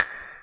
JJPercussion (42).wav